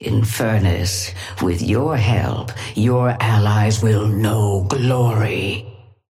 Sapphire Flame voice line - Infernus, with your help, your allies will know glory.
Patron_female_ally_inferno_start_03.mp3